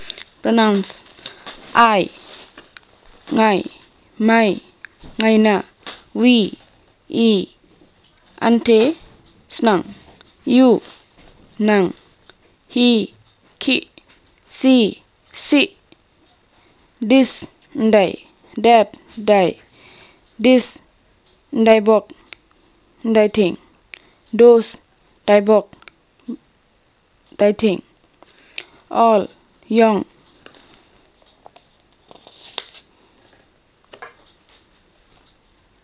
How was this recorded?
dc.description.elicitationmethodQuestionnaire